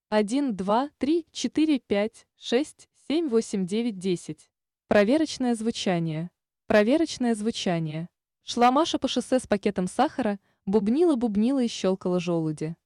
Сформировал в яндекс переводчике звуковой файл, в котором сделал много шипящих, свистящих и бубнящих звуков, закинул его на плейер. Плейер подключил к SSM, а выход SSM к линейному входу звуковой карты компьютера. На компе звук записывал с помощью Adobe Audition.
1. Резистор 200кОм = 0Ом, компрессия 1:1